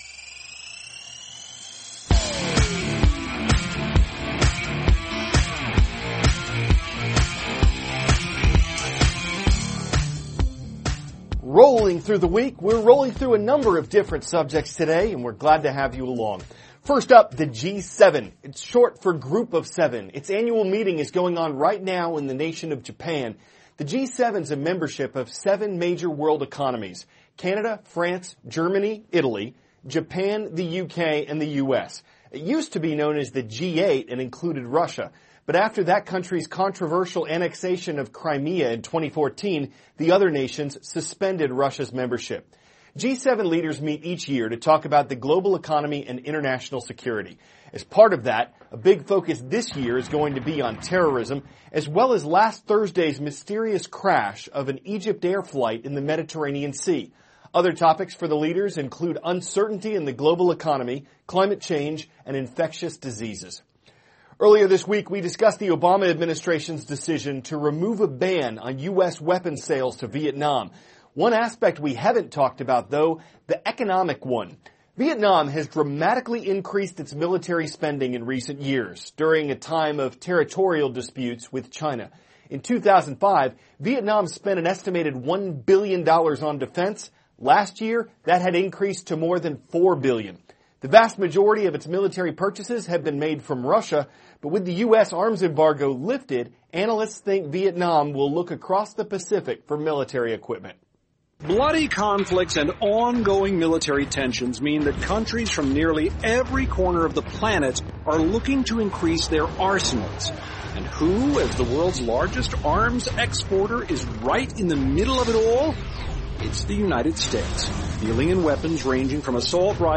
(cnn Student News) -- May 26, 2016